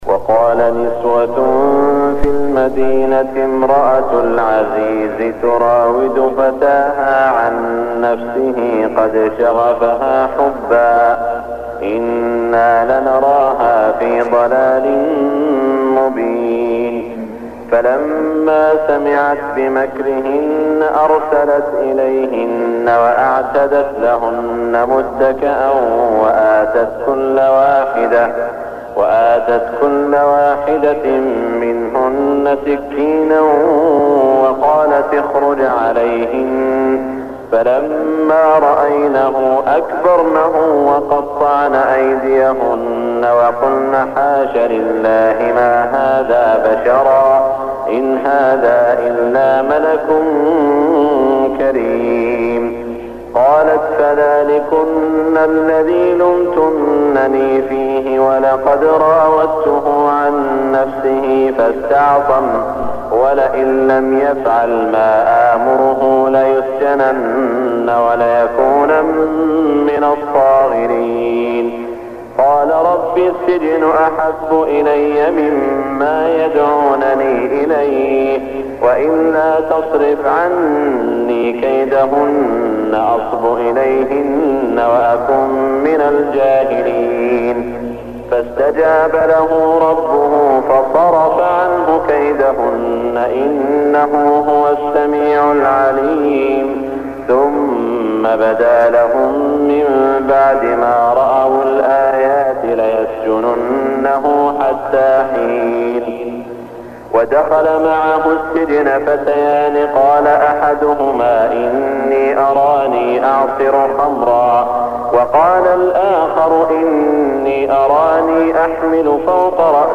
صلاة الفجر 1420 من سورة يوسف > 1420 🕋 > الفروض - تلاوات الحرمين